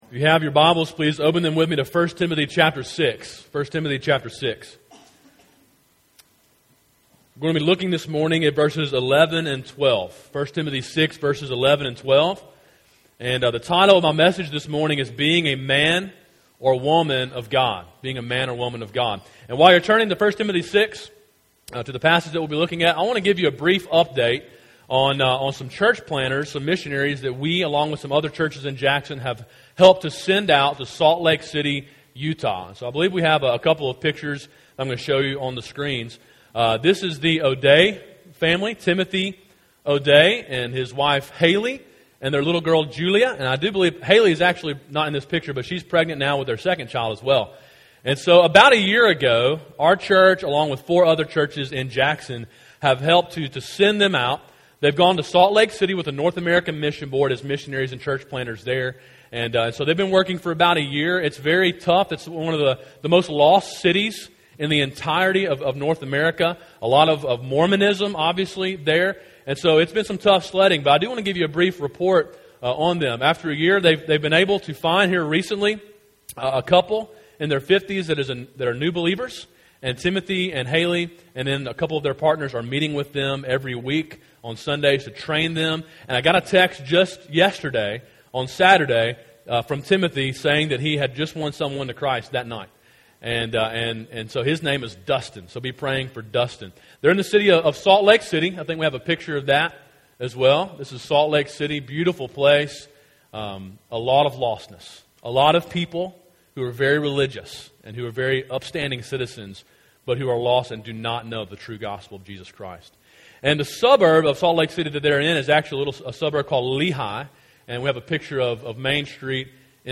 A sermon in a series on the book of 1 Timothy.